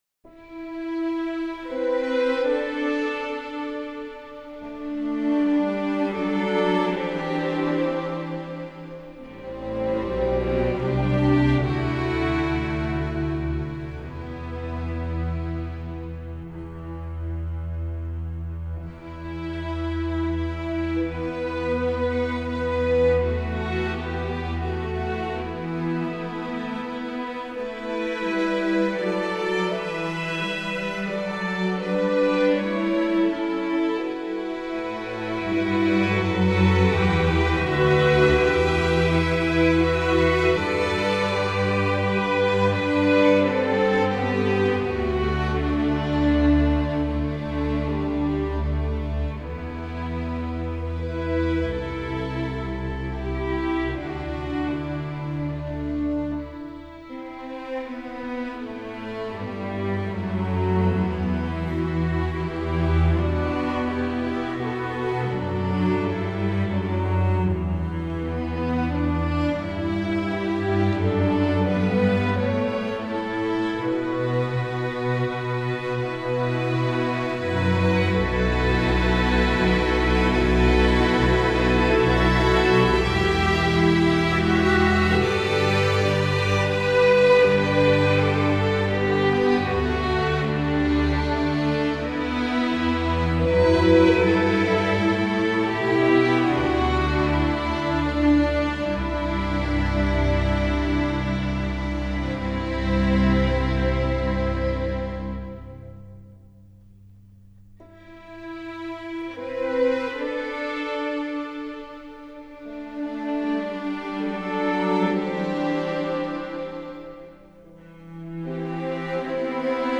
English Folk Song
String Orchestra